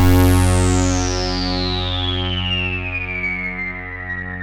KORG F#2 1.wav